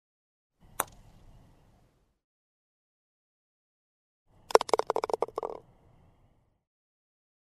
Звуки гольфа
Звук удара клюшкой и попадания мяча в лунку в гольфе: